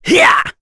Roman-Vox_Attack3.wav